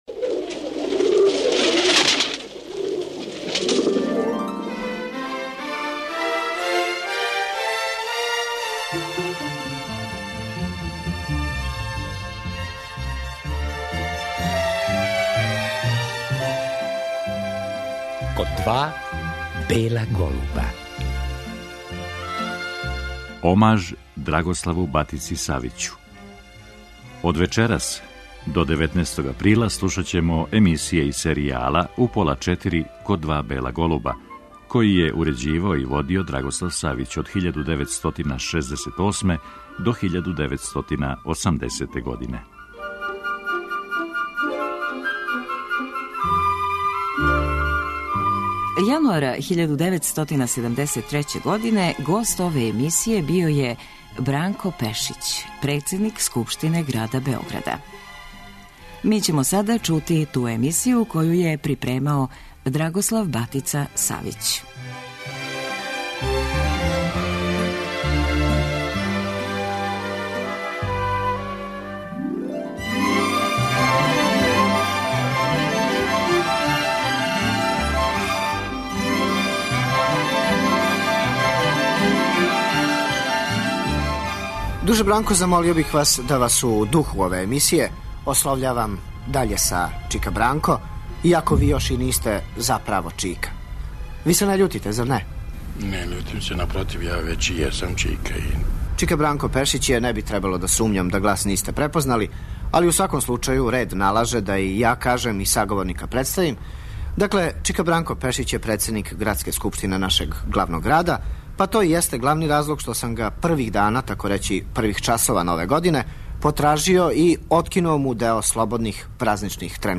Разговори су снимљени седамдесетих година прошлог века.